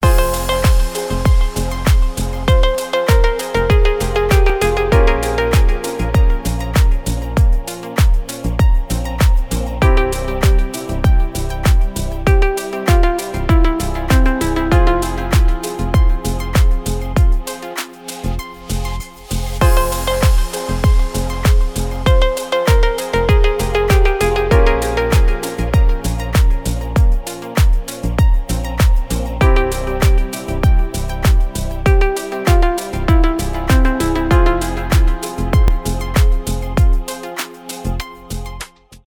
мелодичные , без слов , deep house